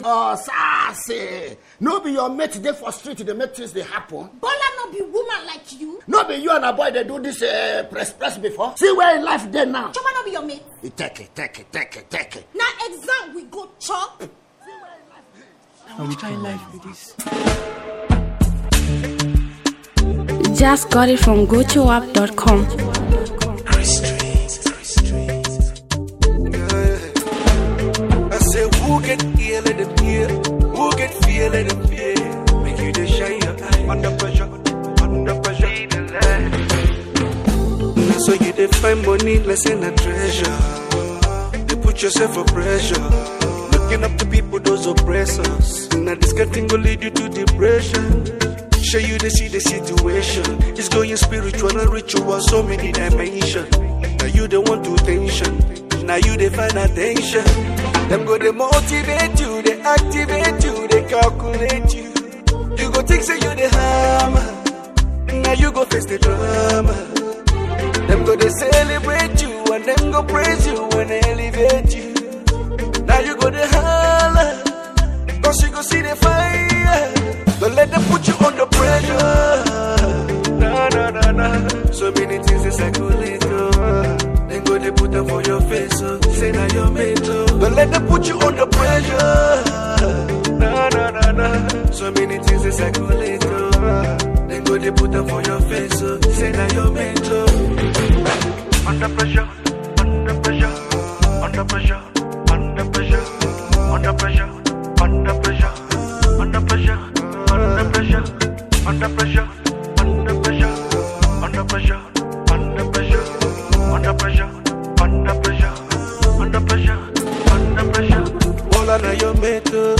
a soulful revolution sound